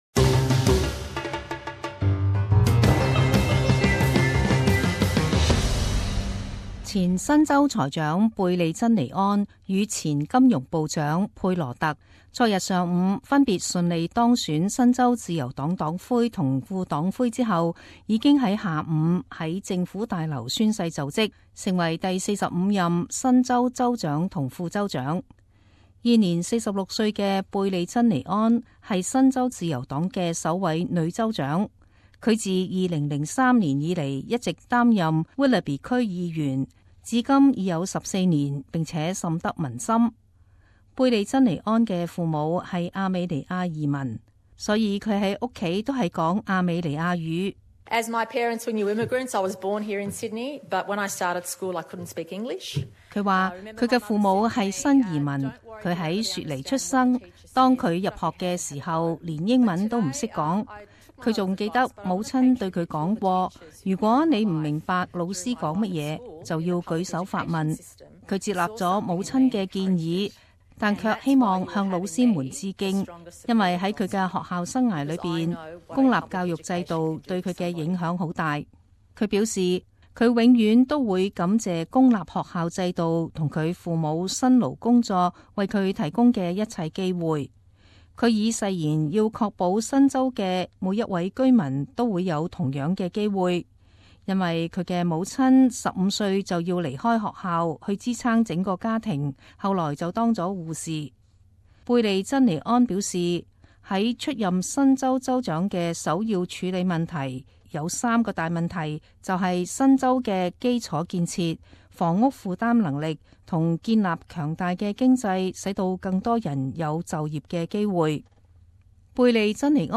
【时事报导】 贝莉珍妮安已宣誓就任新州州长